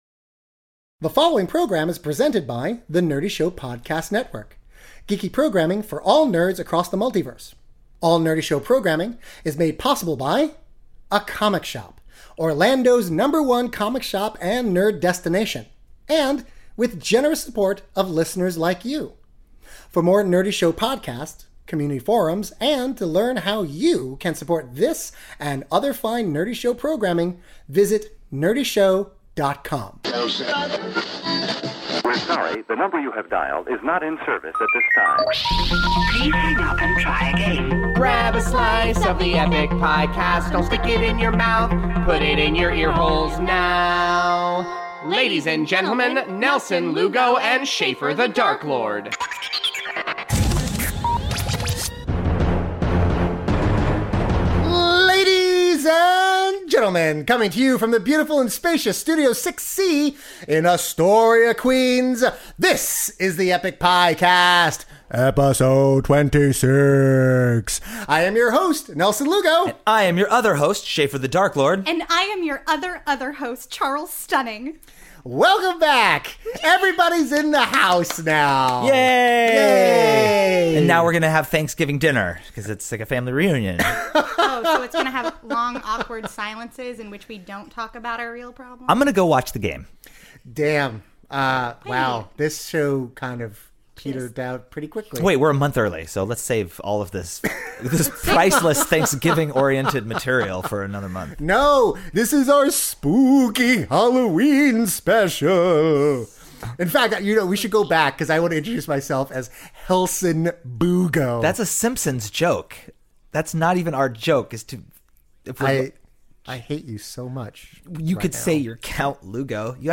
For the first time since June, all three Piecast snarkcasters are in the same studio at the same time for a very special Halloween installment.